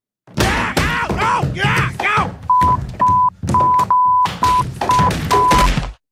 Звуки падения по лестнице